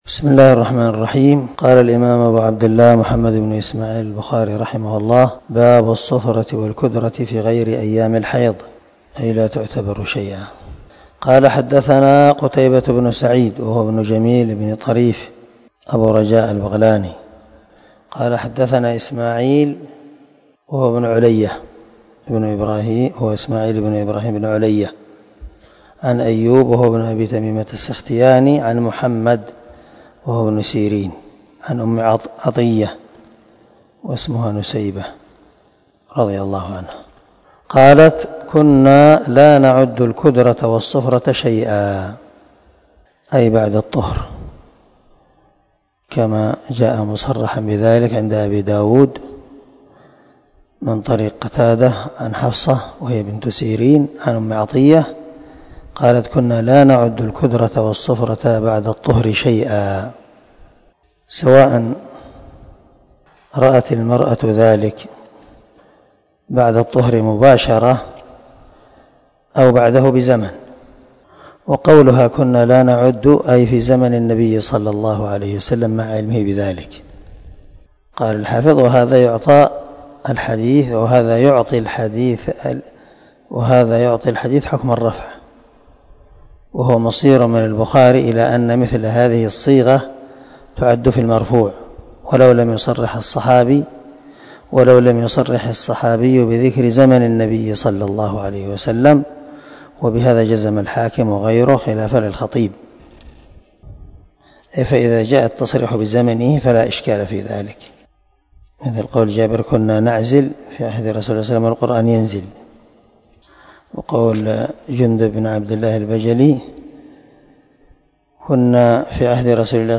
254الدرس 21 من شرح كتاب الحيض حديث رقم ( 326 ) من صحيح البخاري